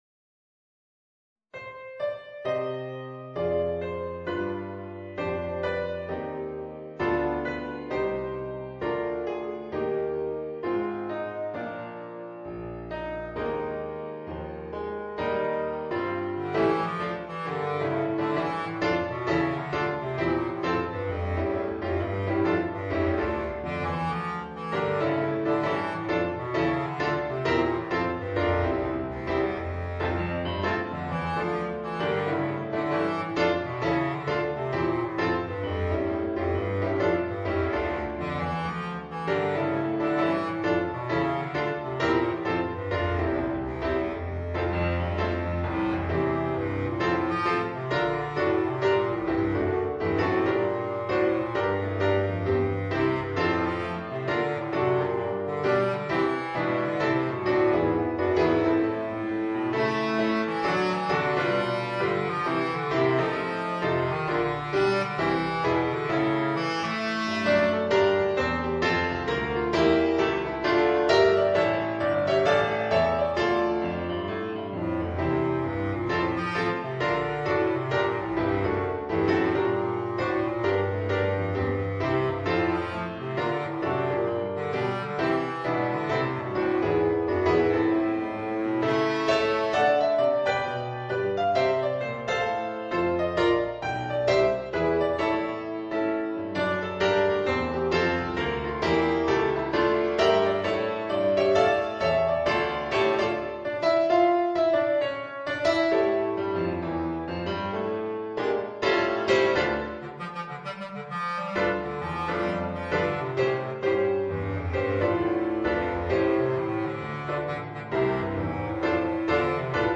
Voicing: Bass Clarinet and Piano